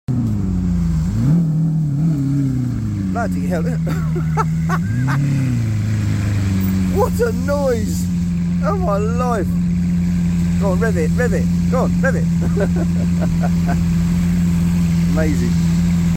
Lamborghini Aventador What a sound effects free download
What a Mp3 Sound Effect Lamborghini Aventador - What a noise!